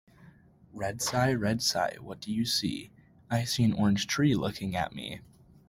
A repetitive, rhythmic chant follows Red Cy as it spots different colored campus sights, ending with the Cyclones seeing them all looking back.